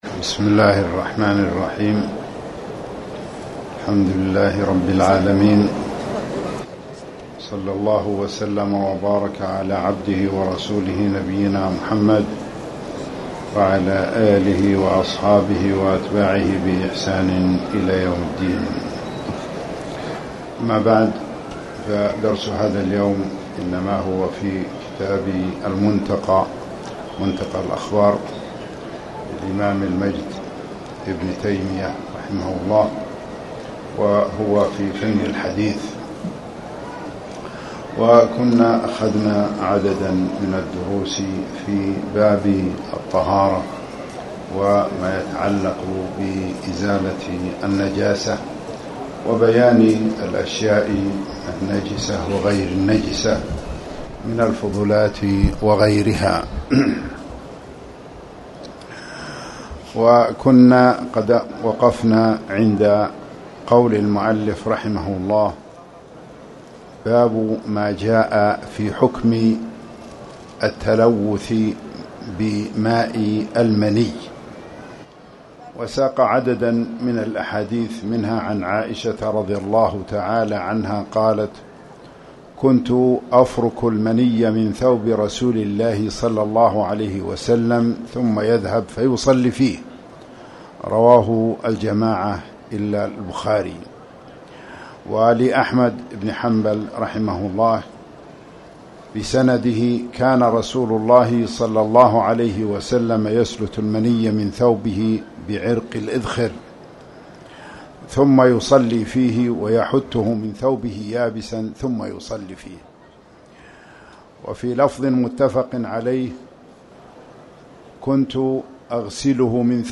تاريخ النشر ١٧ شوال ١٤٣٨ هـ المكان: المسجد الحرام الشيخ